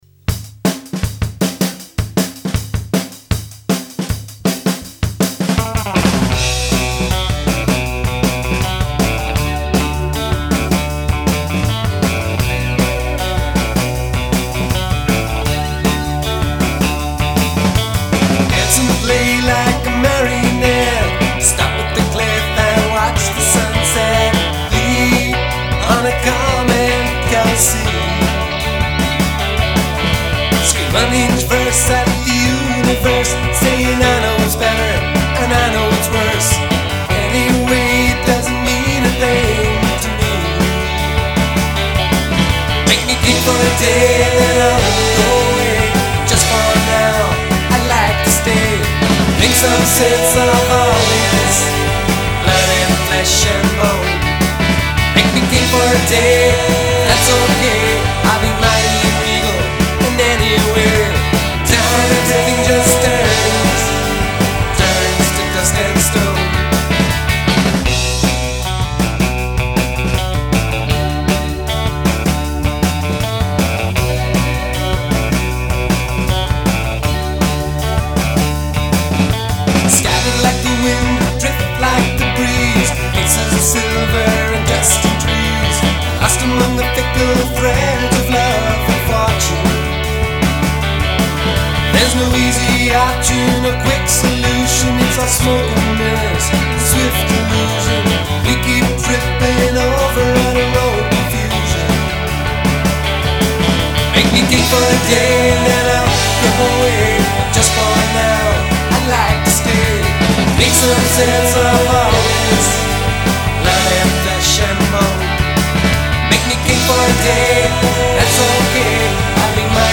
I played in a band called Stride in the late 90's. All original material, all penned by yours truly.
The recording was rough and ready, we set up the drums in a concrete basement, not a ideal environment.
We then stuck some borrowed mics up and hit record. The song is a first take, with some extra guitars and vocals overdubbed later in my home studio.
I think it has a certain garage band charm. I really like my guitar solo, which was improvised on a single take.